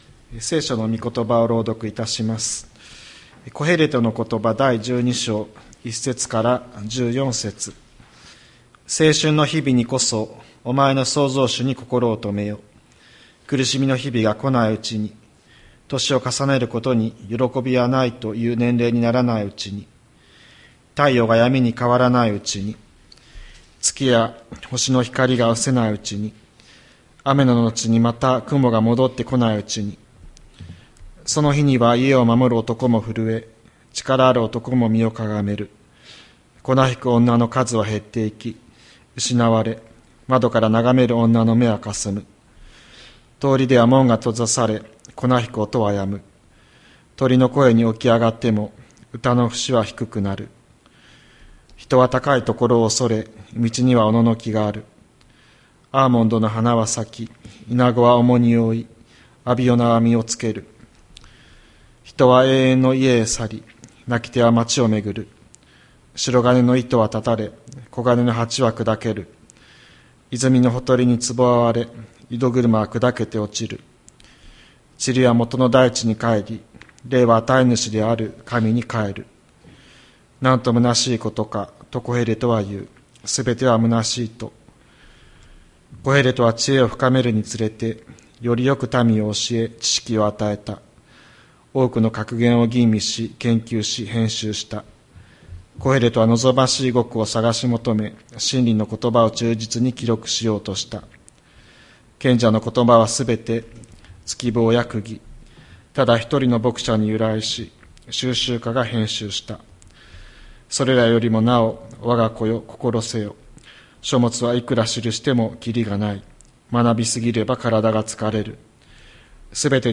千里山教会 2025年03月23日の礼拝メッセージ。